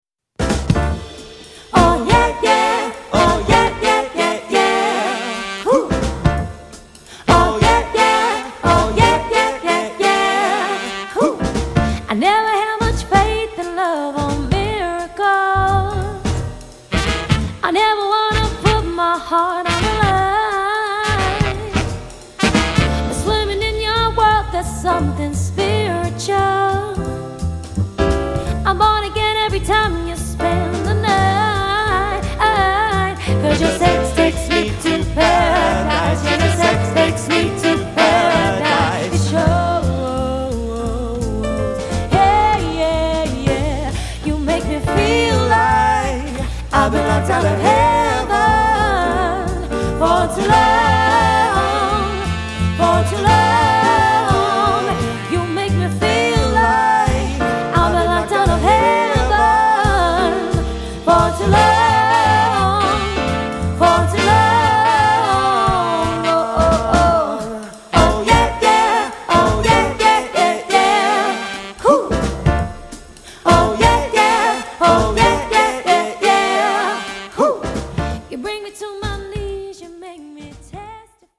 1. Charismatic postmodern swing, soul and pop classics